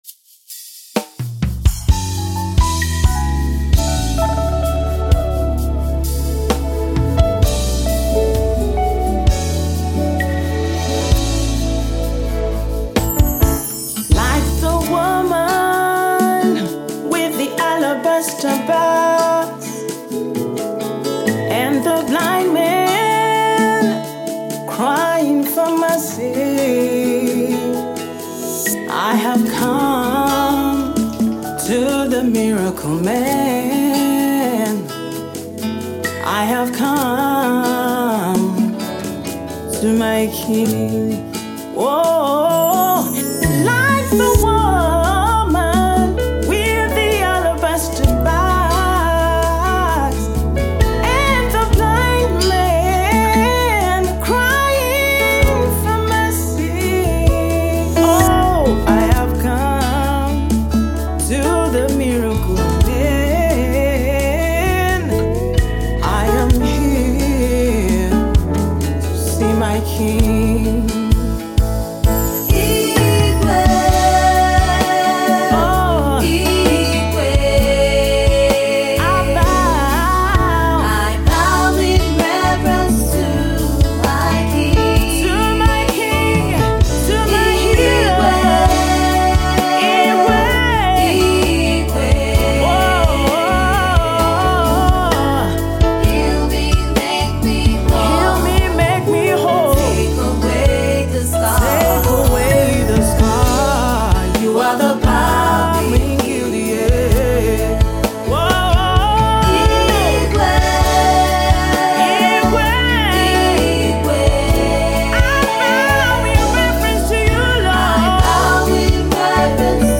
slow worship song